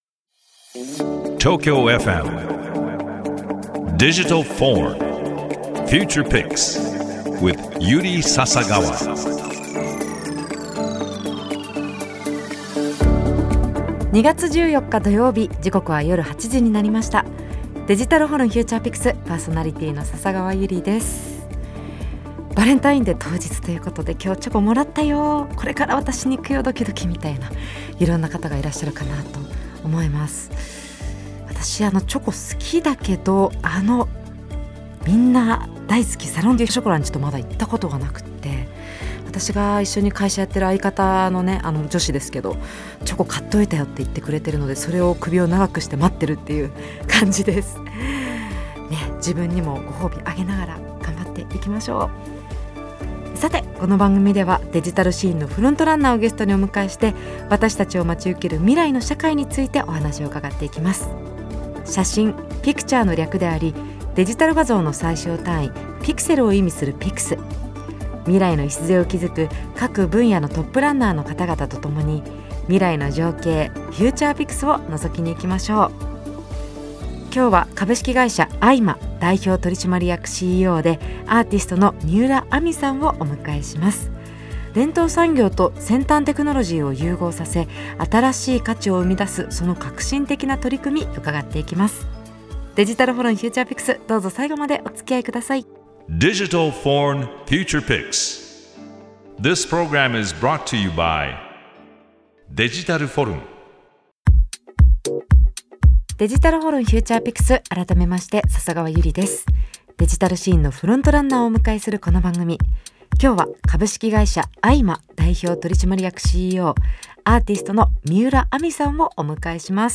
デジタルシーンのフロントランナーをゲストにお迎えして、 私達を待ち受ける未来の社会についてお話を伺っていくDIGITAL VORN Future Pix。